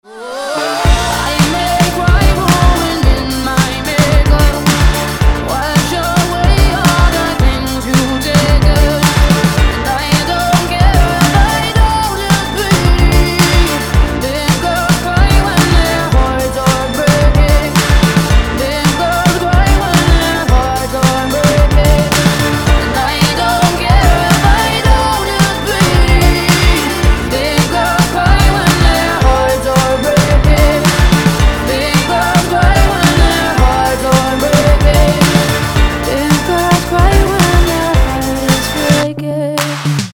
dance
Electronic
Electropop
Electropop ремикс песни Австралийской певицы